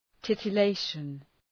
Προφορά
{,tıtə’leıʃən}